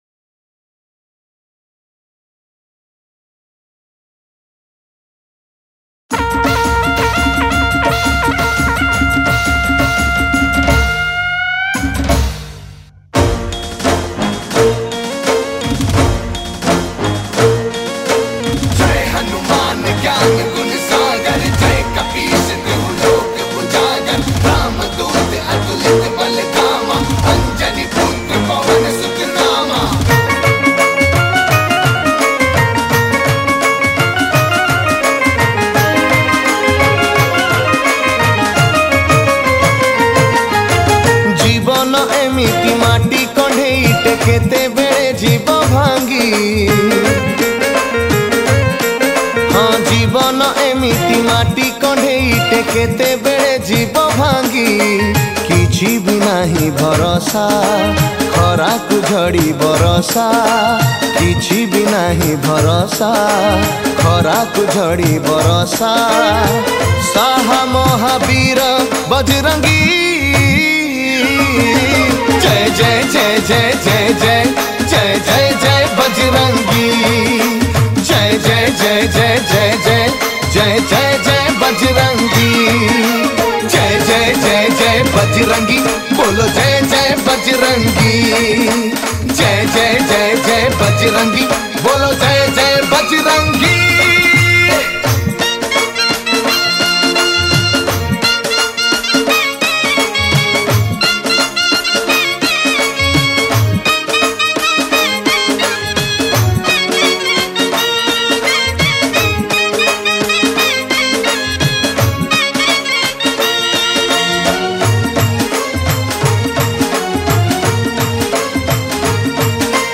Hanuman Bhajan